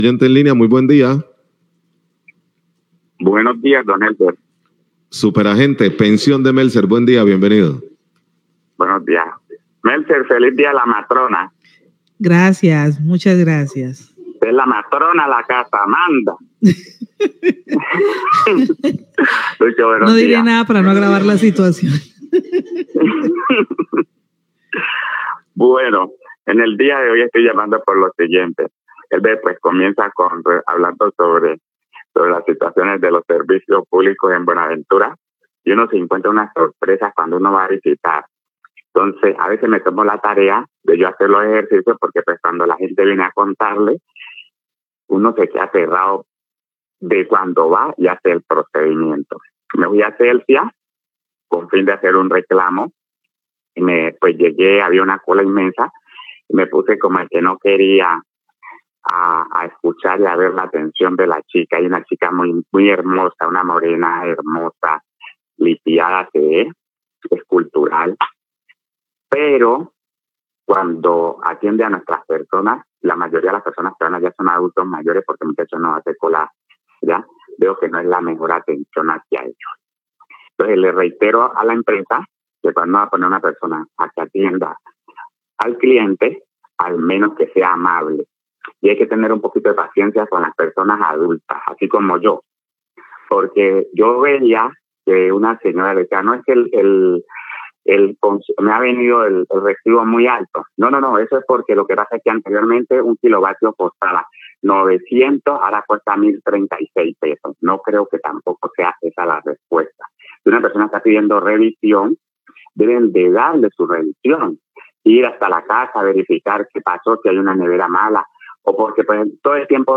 Oyente manifiesta su inconformidad con atención en la de la empresa Celsia
Radio